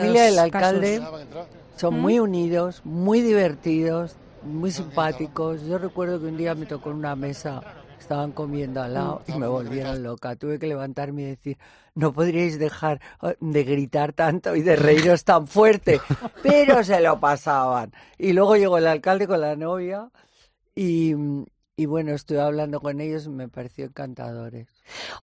En 'Fin de Semana' con Cristina López Schlichting no perdimos detalle de la llegada de los cientos de personas que acudieron al enlace y te contamos, el minuto a minuto, con Carmen Lomana.